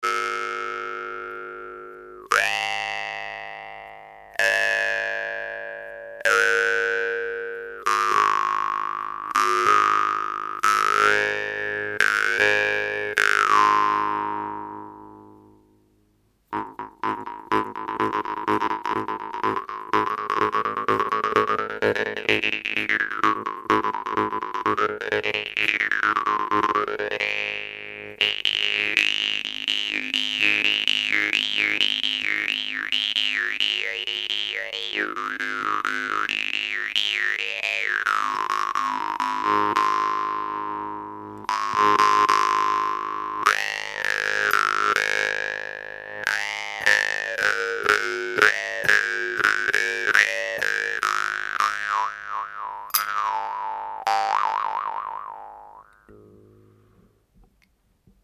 Guimbarde massive, ALIKORN offre un long sustain et des vibrations qui s’atténuent en douceur.
Ce son bas est envoutant et magique…super pour des jeux méditatifs.